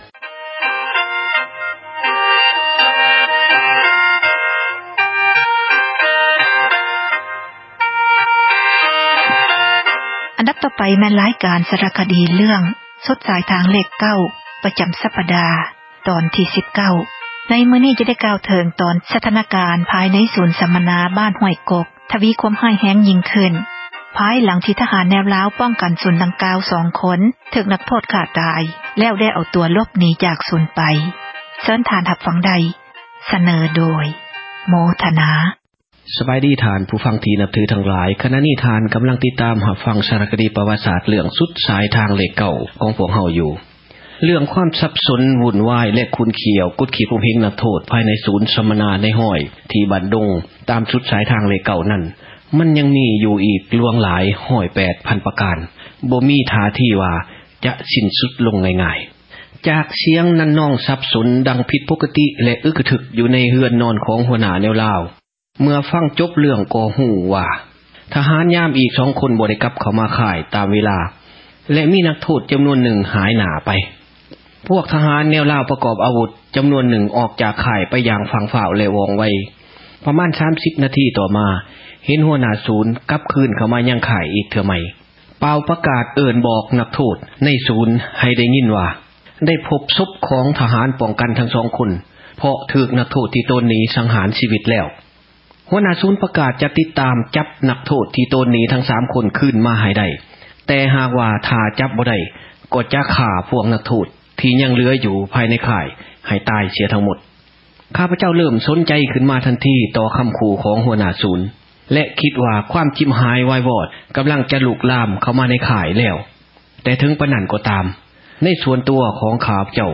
ສາຣະຄະດີ ສຸດສາຍທາງເລຂ 9 ຕອນທີ 19. ຈະກ່າວເຖິງ ສະຖານະການ ພາຍໃນສູນ ສັມມະນາ ບ້ານຫ້ວຍກົກ ຍິ່ງທະວີ ຄວາມຮ້າຍແຮງ ພາຍຫລັງ ທະຫານແນວລາວ ປ້ອງກັນສູນ ດັ່ງກ່າວ ຖືກນັກໂທດ ຂ້າຕາຍ ແລ້ວເອົາຕົວ ຫລົບໜີ ໄປ.